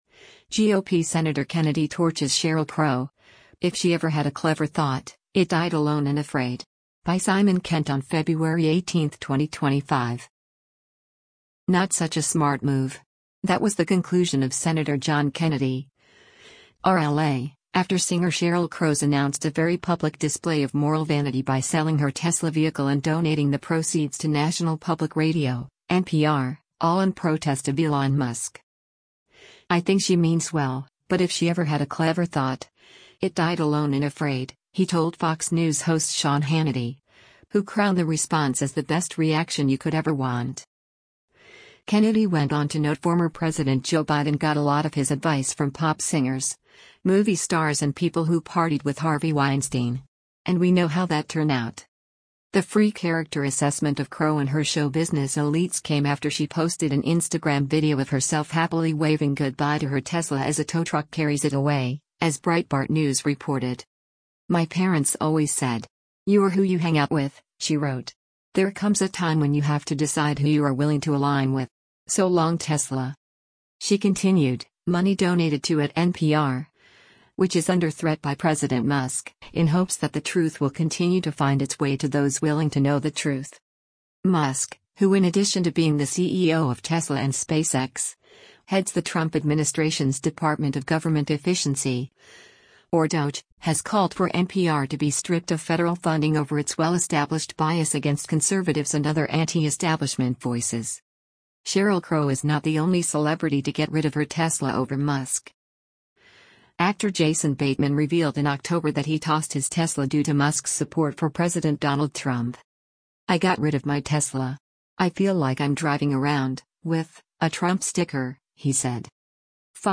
“I think she means well, but if she ever had a clever thought, it died alone and afraid,” he told Fox News host Sean Hannity, who crowned the response as  “the best reaction you could ever want.”